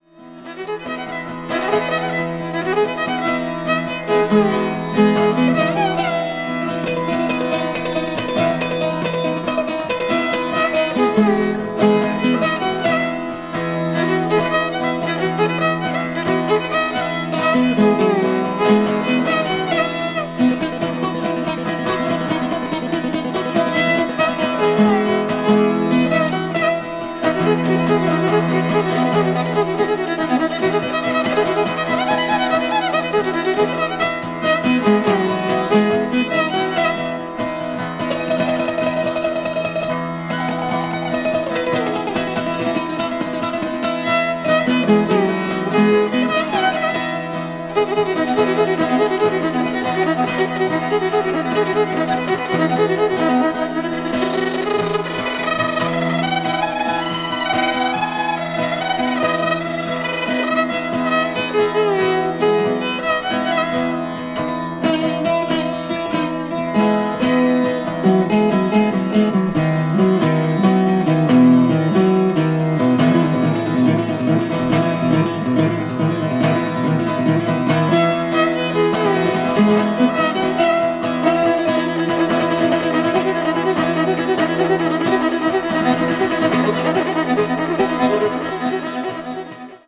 contemporary jazz   ethnic jazz   guitar solo